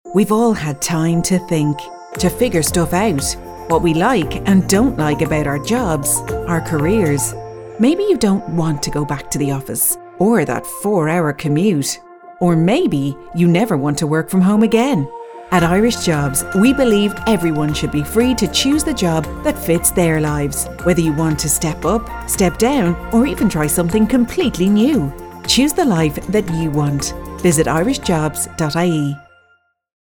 Radio
Irish-Jobs-ie-Radio-Ad-November-2021.mp3